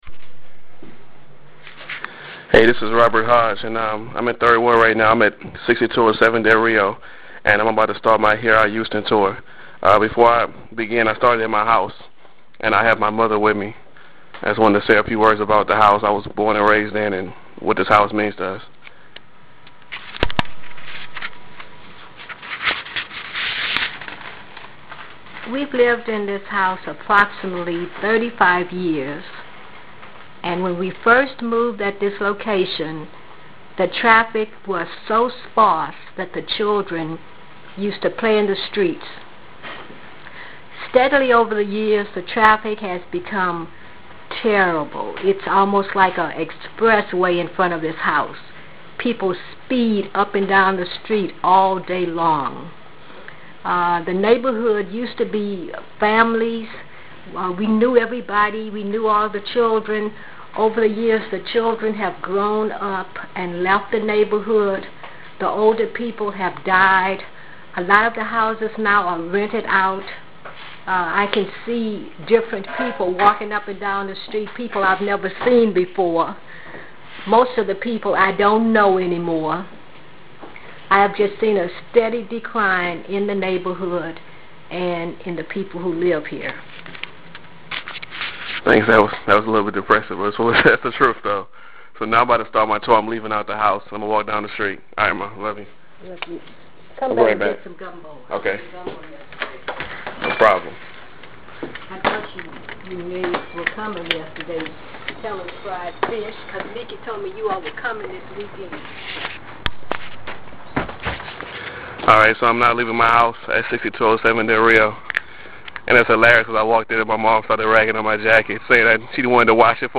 audio walking tours